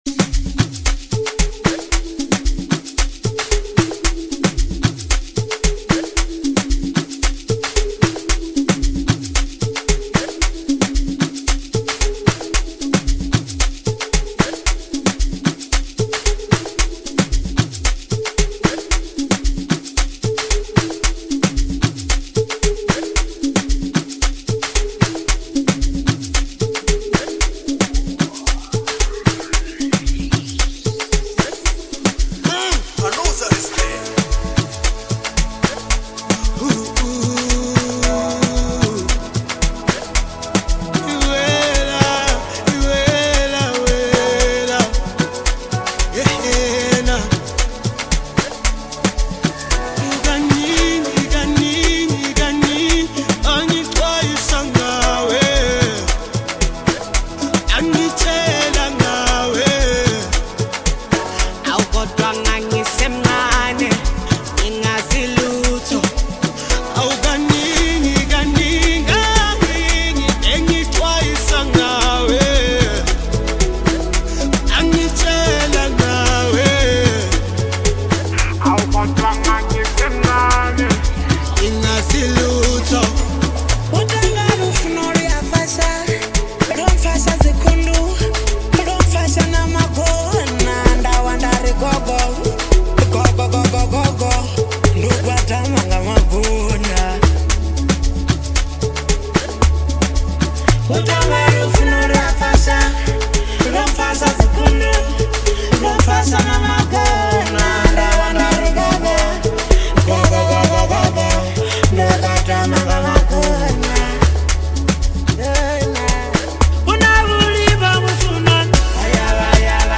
powerful vocals
soulful melodies